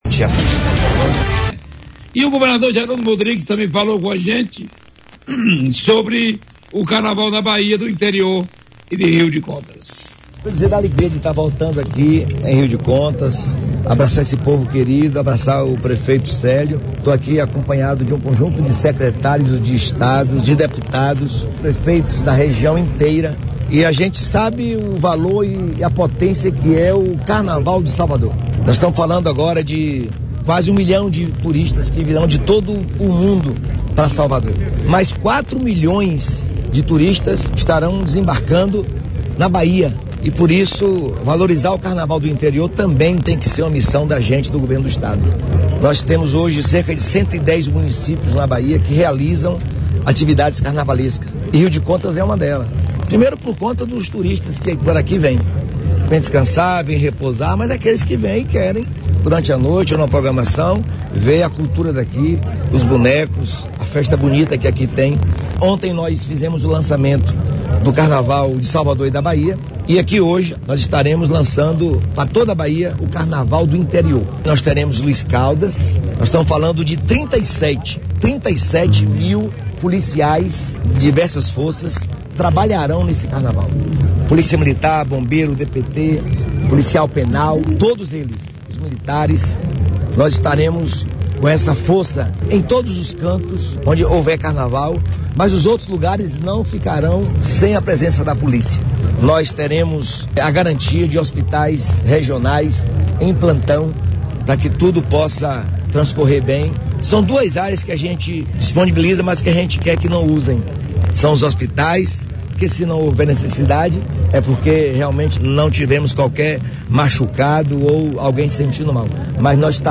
Em Rio de Contas, durante entrevista coletiva, o governador Jerônimo Rodrigues Souza comentou sobre diversos assuntos direcionados à Joia do Sertão Baiano, entre eles está o referido equipamento que poderá ocupar parte da área do extinto Aeroporto Pedro Otácilio Figueiredo, que fica no bairro Patagônia com fácil acesso pelas Avenidas Paraná e Frei Benjamin.